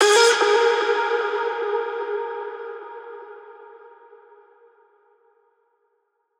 VR_vox_hit_exhale_D#.wav